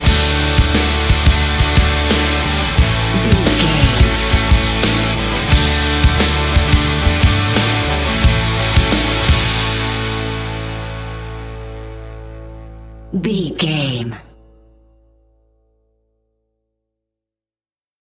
Ionian/Major
D
drums
electric piano
electric guitar
bass guitar
Sports Rock
hard rock
aggressive
energetic
intense
nu metal
alternative metal